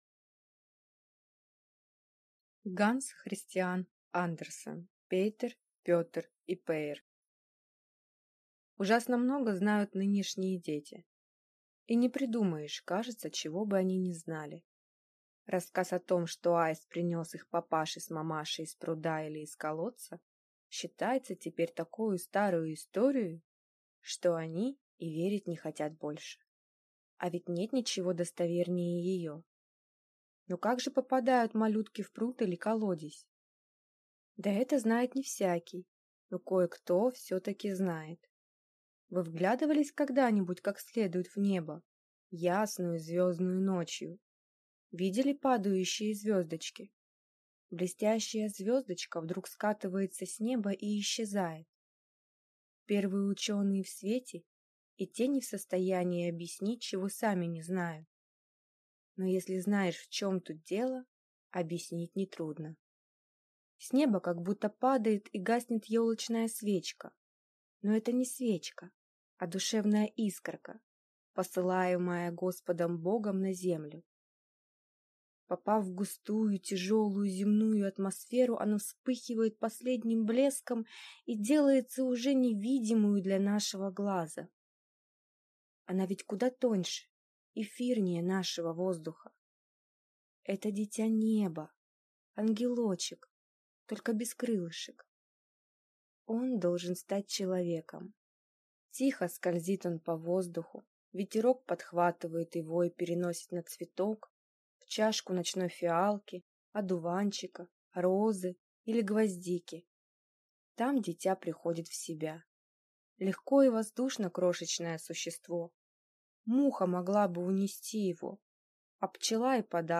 Аудиокнига Пейтер, Пётр и Пейр | Библиотека аудиокниг
Прослушать и бесплатно скачать фрагмент аудиокниги